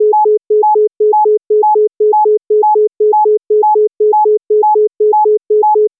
Auditory streaming examples
When one tone is much higher than the other, the galloping rhythm can disappear. In the next example, you will probably hear two separate sequences of regularly repeating tones at the same time - one high and one low.